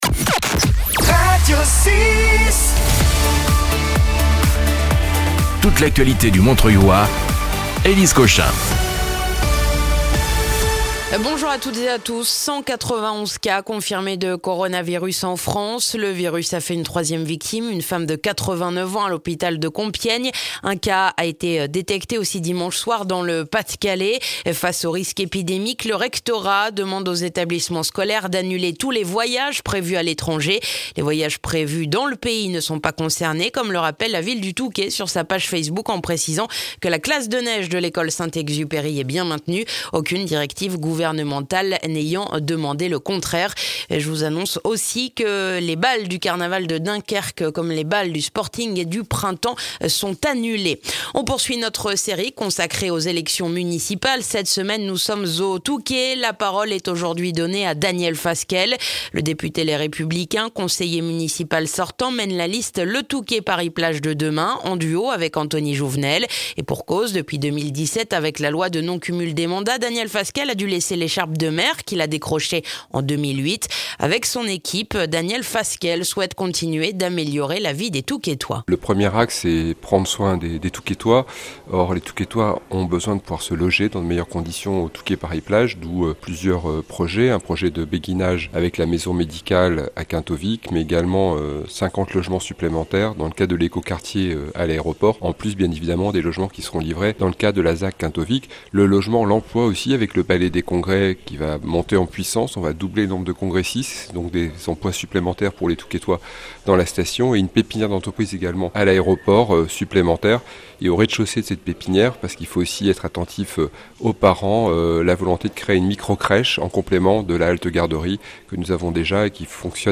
Le journal du mardi 3 mars dans le montreuillois